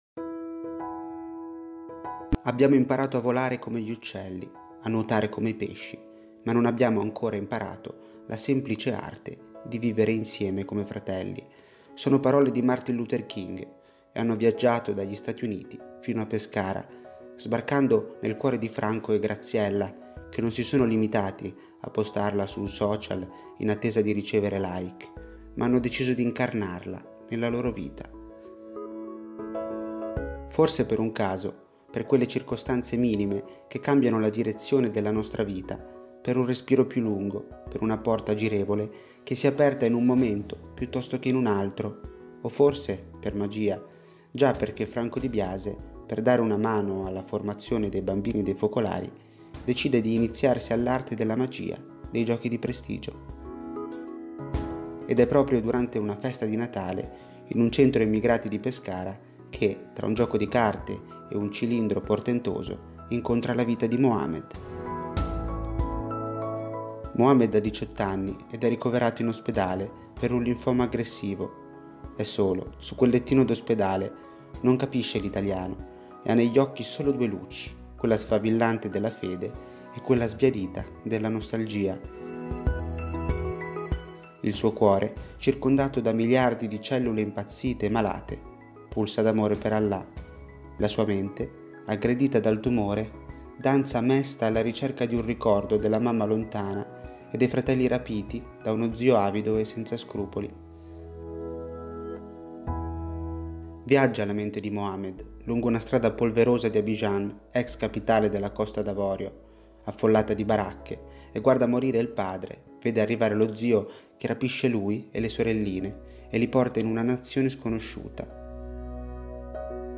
Storie > Audioletture